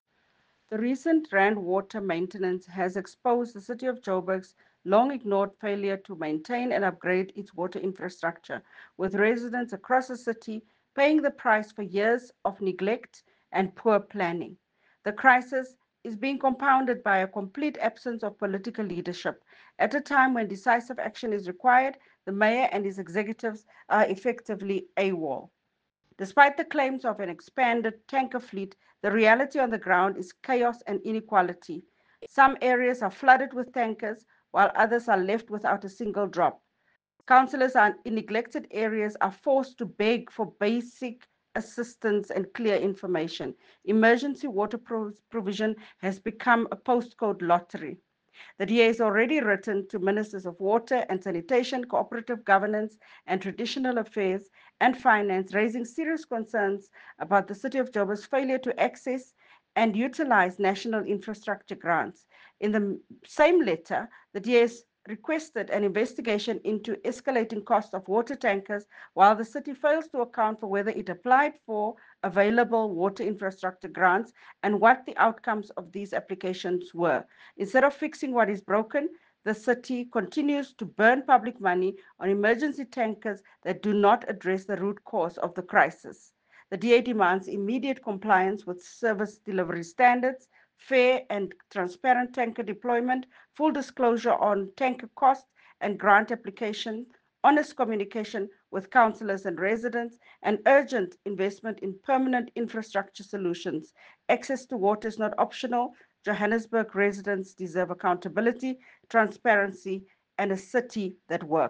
English soundbite by Belinda Kayser-Echeozonjoku